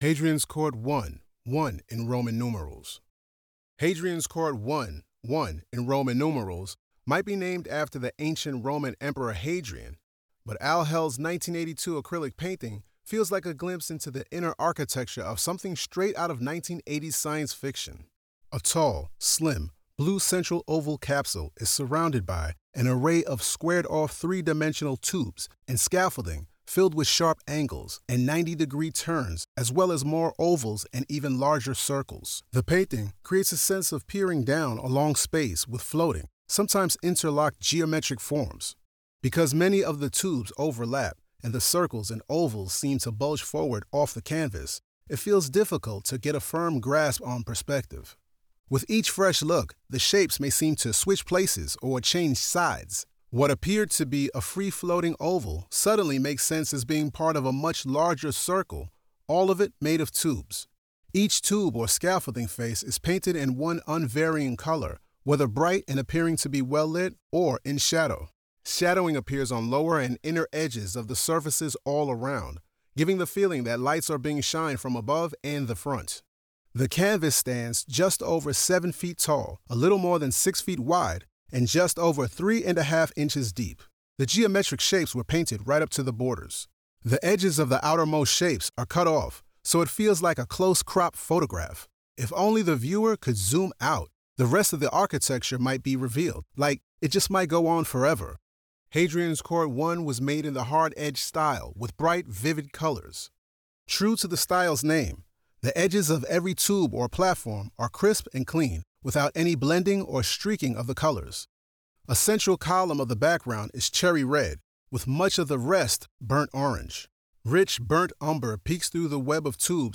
Audio Description (03:04)